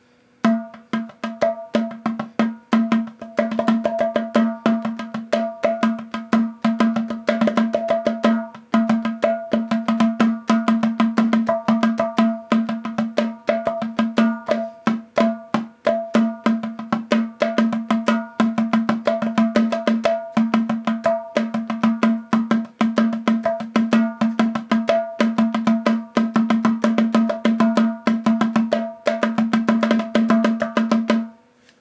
Gourd Hand Drum 002
Basic Hand Drum with calfskin head, oiled bronze finish on shell, and hemp trim sealed with brown acrylic.
BasicHandDrum_002_Final.wav